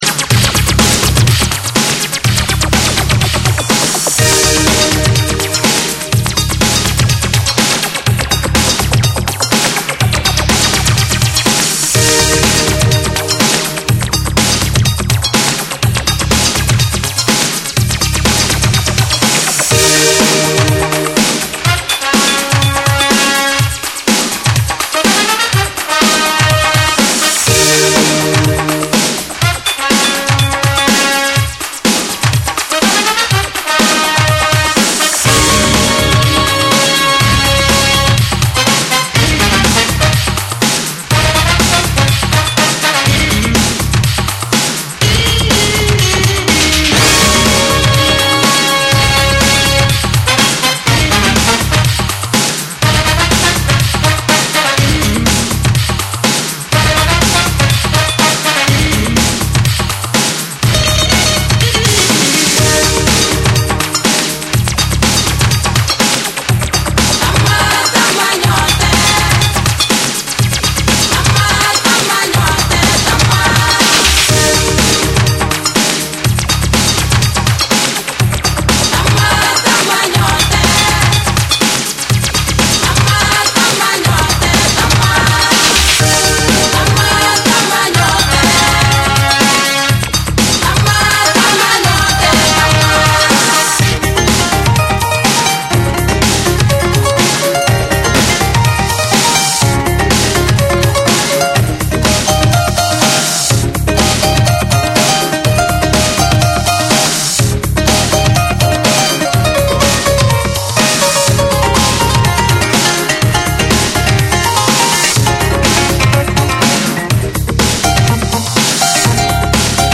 伝統的なアフリカ音楽とエレクトロ・ビートを融合させたハイブリッドなサウンドを展開。
WORLD / TECHNO & HOUSE